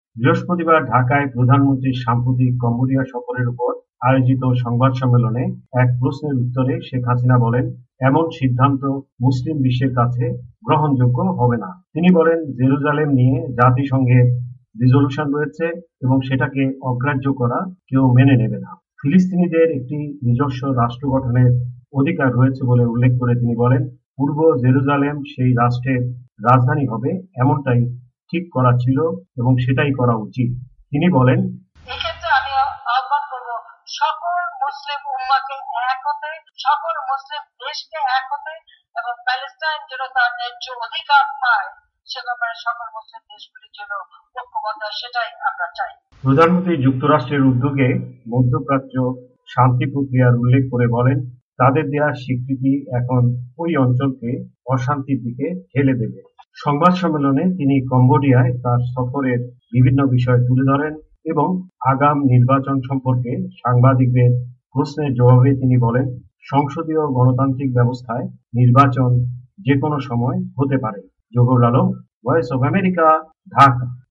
বৃহস্পতিবার ঢাকায় প্রধানমন্ত্রীর সাম্প্রতিক কম্বোডিয়া সফরের ওপর আয়োজিত সংবাদ সম্মেলনে এক প্রশ্নের উত্তরে শেখ হাসিনা বলেন এমন সিদ্ধান্ত মুসলিম বিশ্বের কাছে গ্রহণ যোগ্য হবে না।